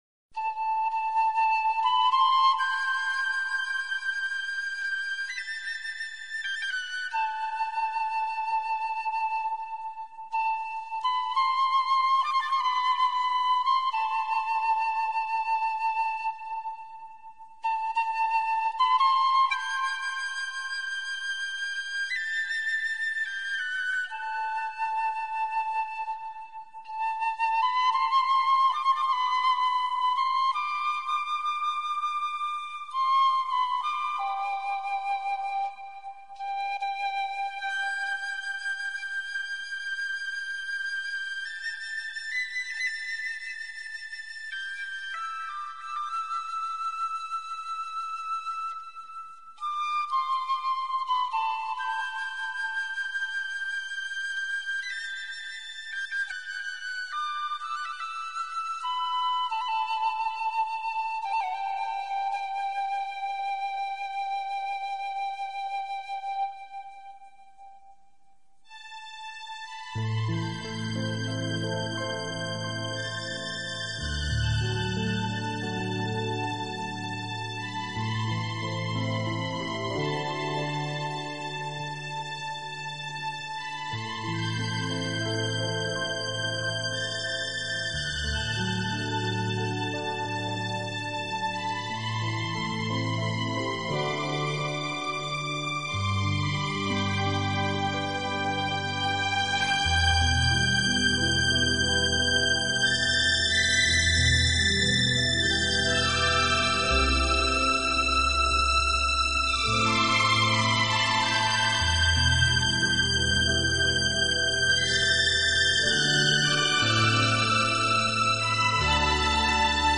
特运用了很多民族特色乐器如风笛、班卓琴、曼陀铃等，配以他惯用的电子合成器模拟
的弦乐，营造出浓浓的爱尔兰土风旋律。